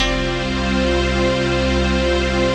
CHRDPAD020-LR.wav